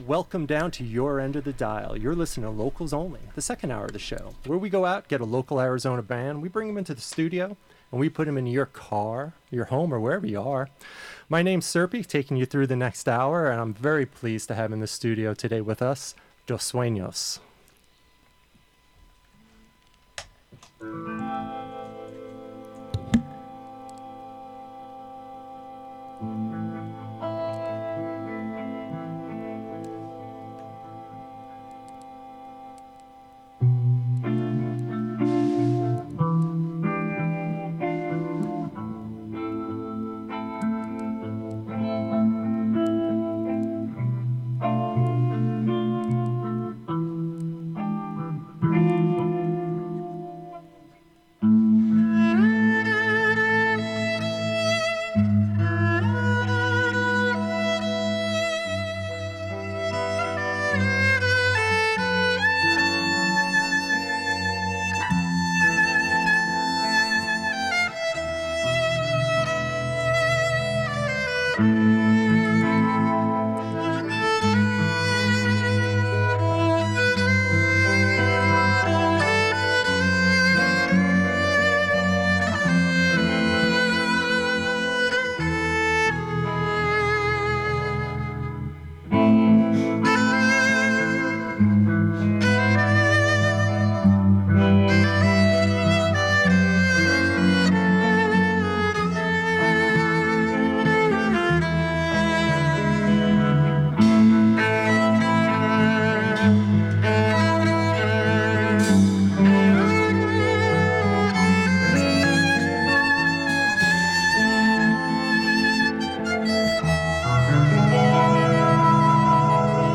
live in Studio 2A
Listen to the live performance + interview here!
Live Music , Live Studio Session